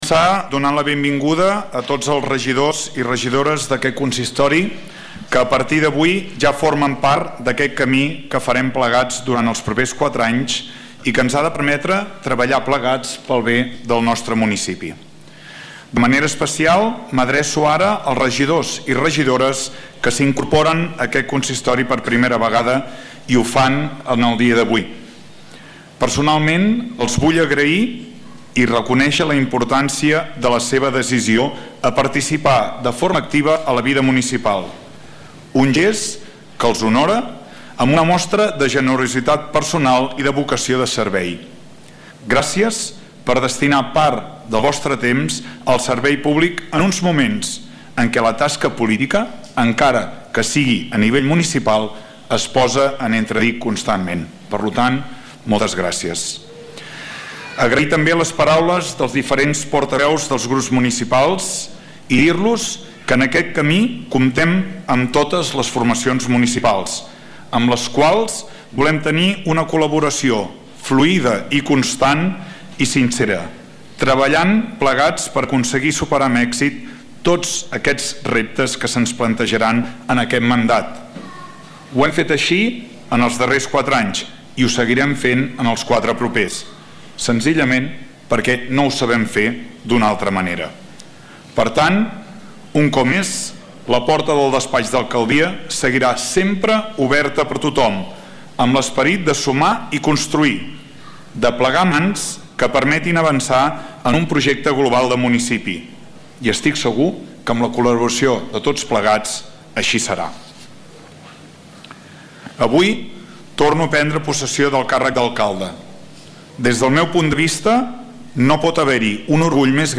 Abans d’acabar el discurs, Lluís Puig s’ha emocionat quan ha recordat els seus familiars, entre llàgrimes i aplaudiments, l’alcalde de Palamós ha nombrat aquelles persones que sempre han estat al seu costat i li han donat suport.
Escolta el discurs d’investidura de Lluís Puig.